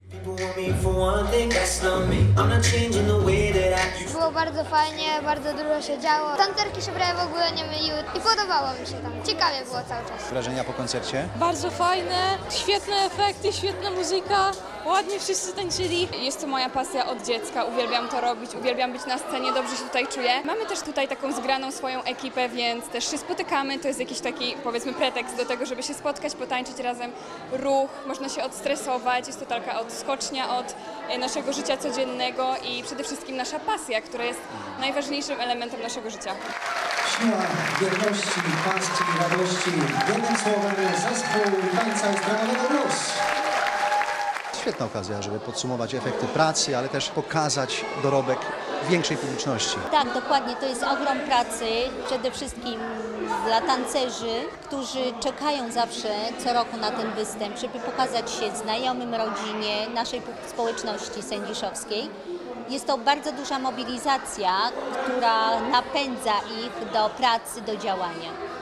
Układy taneczne, śpiew, emocje i owacje – to wszystko można było zobaczyć i przeżyć podczas wydarzenia w miejscowym Domu Kultury.